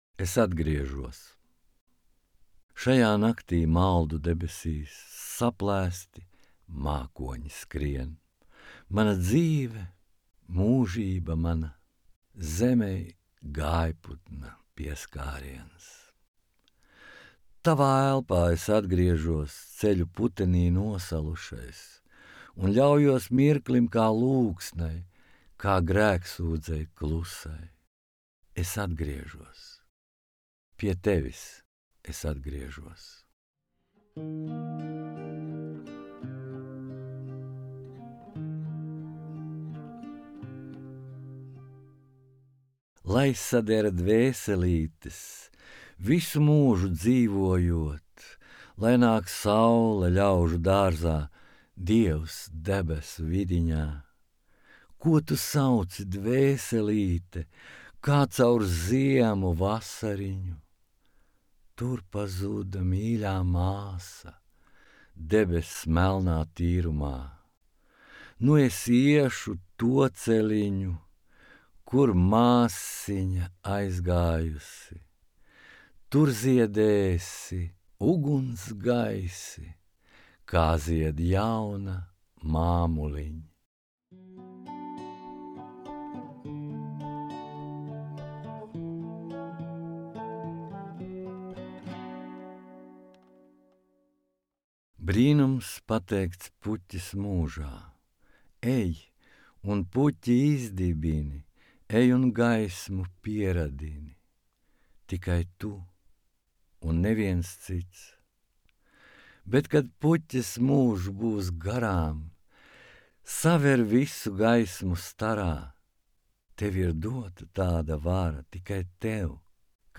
Klausāmgrāmata